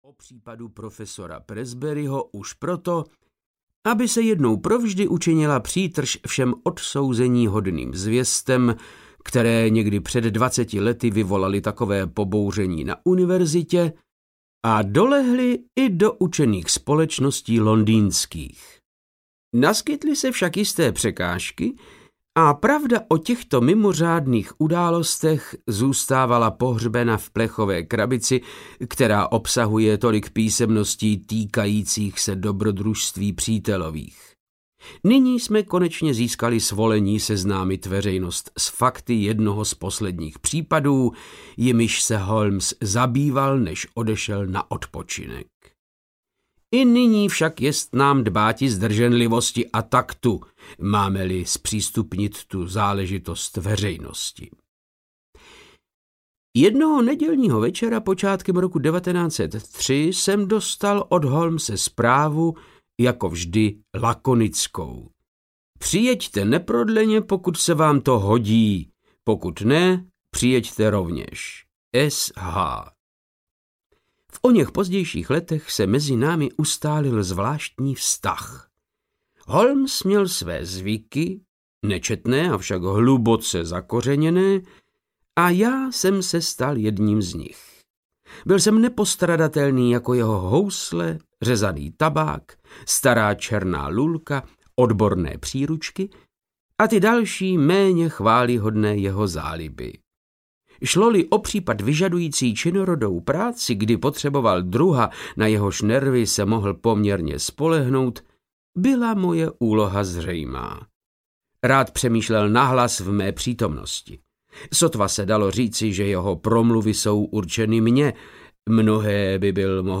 Šplhající muž audiokniha
Ukázka z knihy
• InterpretVáclav Knop